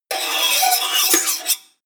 Gemafreie Sounds: Küche